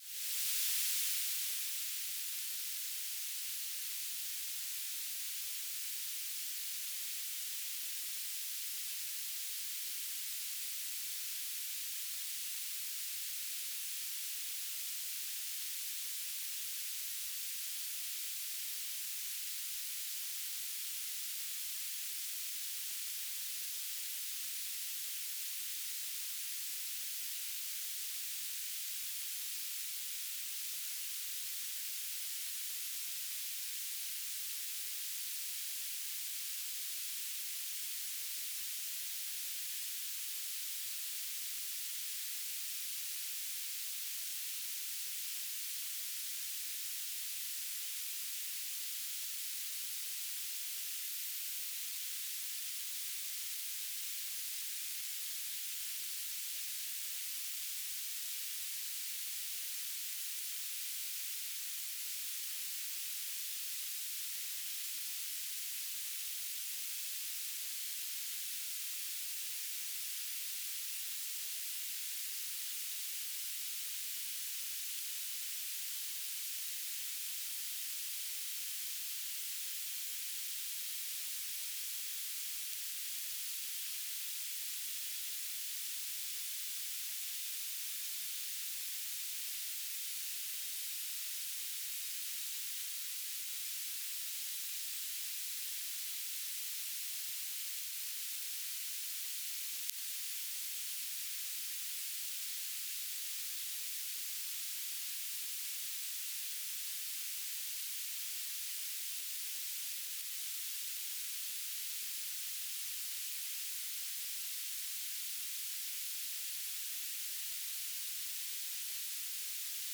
"transmitter_description": "Mode U - BPSK1k2 - Beacon",